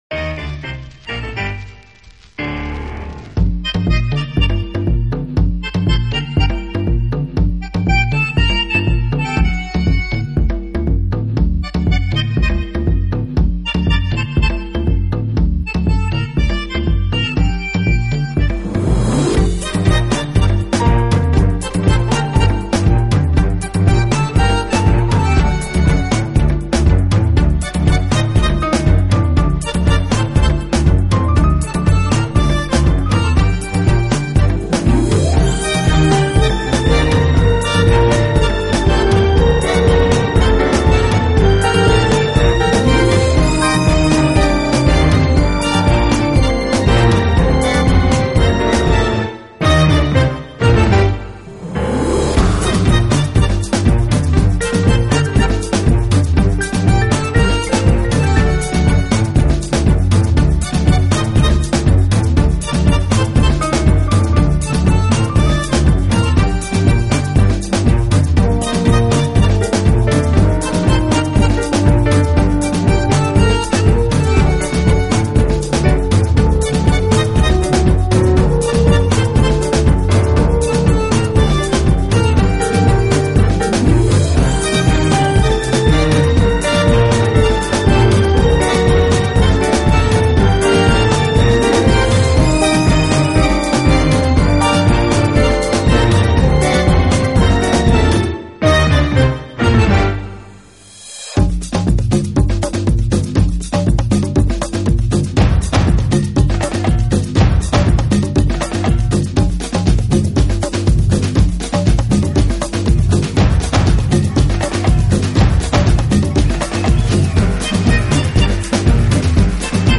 Genre: Lo-Fi / Lounge / Tango
CD 1 - Lounge Side